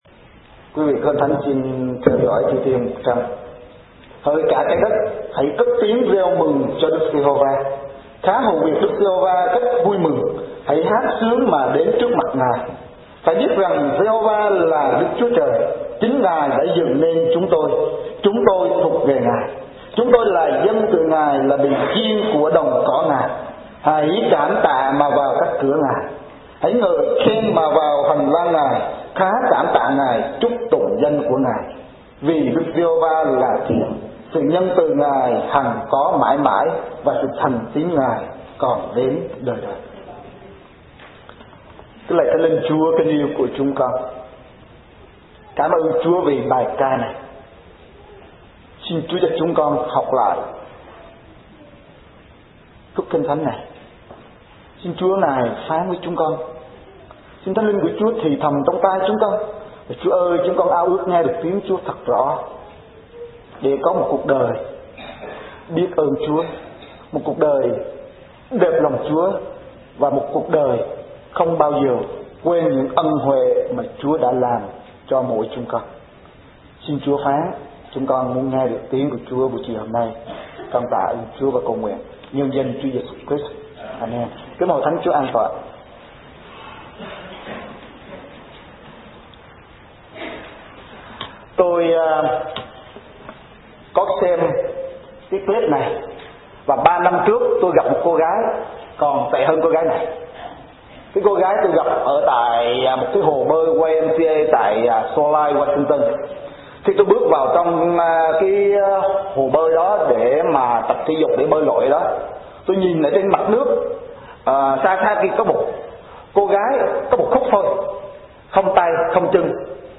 Giảng Luận